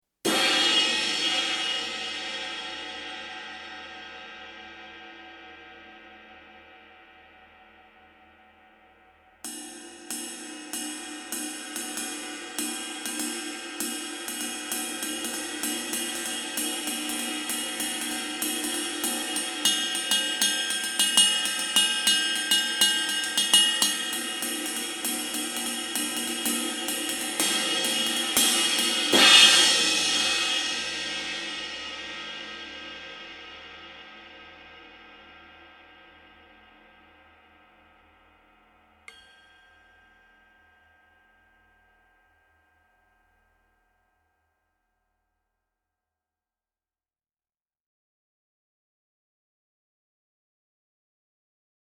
Bei dem Beckensatz handelt es sich um Ride (20"), Crash (16") und HiHat (14"), allesamt aus B20 Legierung und von Hand gehämmert.
Das Ridebecken liefert einen dunkles aber prägnantes Ping und einen klaren Kuppensound. Für ein Becken dieser Preisklasse hat es auch eine bemerkenswerte Portion Charakter, der aber deutlich unter dem Ping angesiedelt ist.
Ride Sample
Ride.mp3